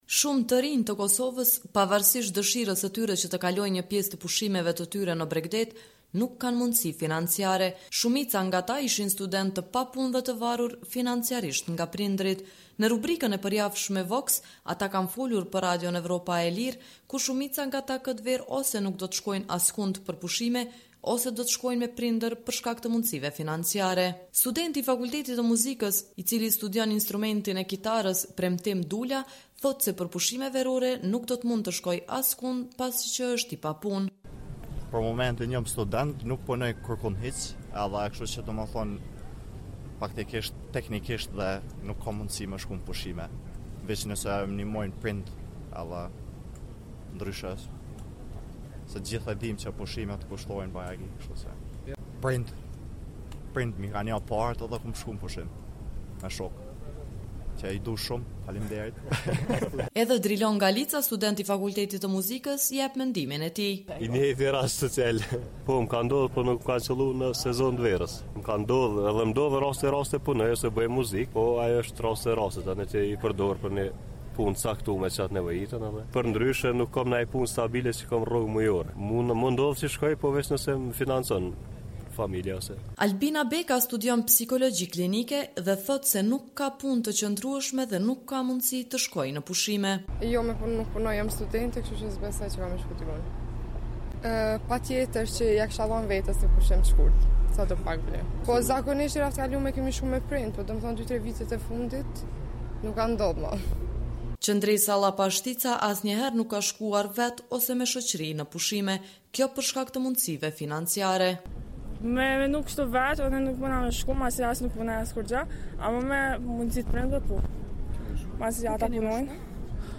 Në rubrikën e përjavshme “VOX”, të Radios Evropa e Lirë, shumica e të anketuarve tregojnë se këtë verë ose nuk do të shkojnë askund për pushime, ose do të shkojnë me prindër për shkak të pamundësive financiare.